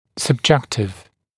[səb’ʤektɪv][сэб’джэктив]субъективный